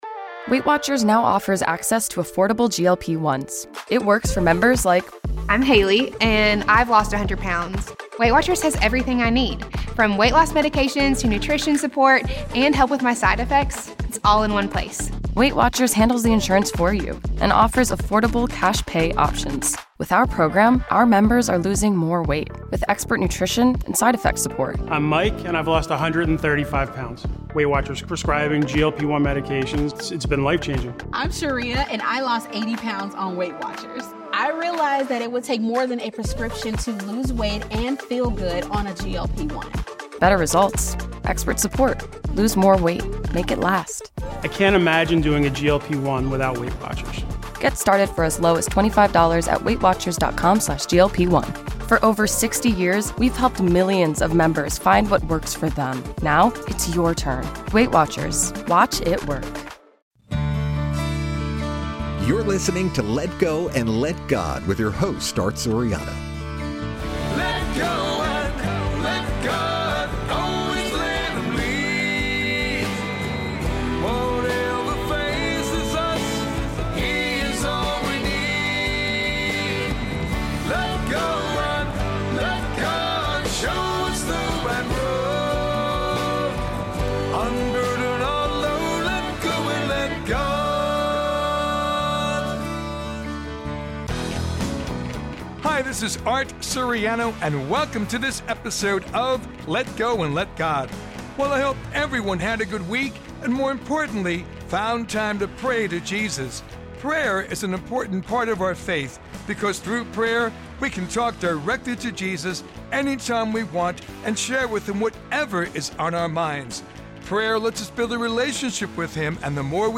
Why Family Is Essential with Guest: Bishop Kevin Sweeney of the Diocese of Paterson, New Jersey – Lone Star Podcast Network